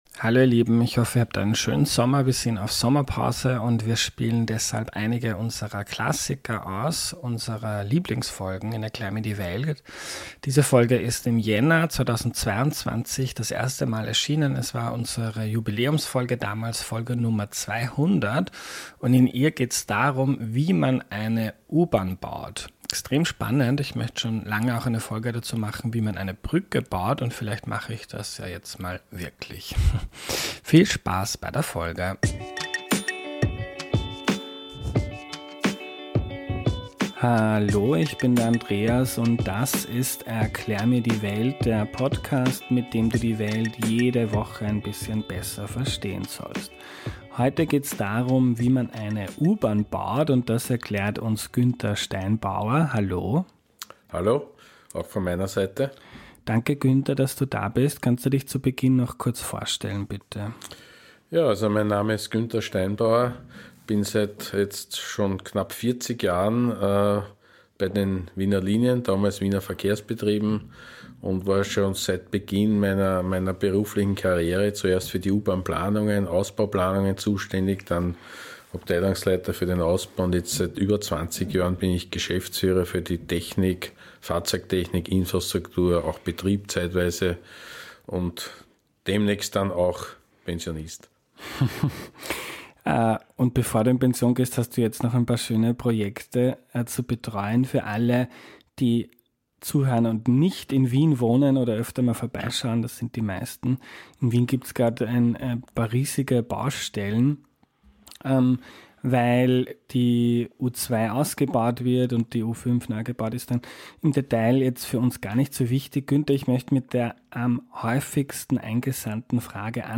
Beatbox am Ende